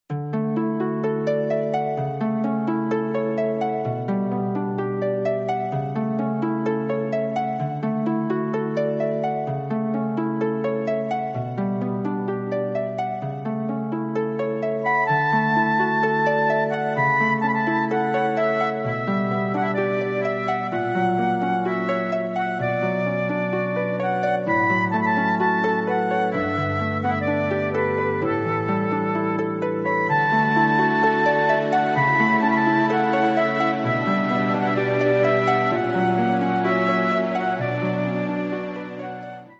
• ファイルサイズ軽減のため、音質は劣化しています。
シンセサイザー演奏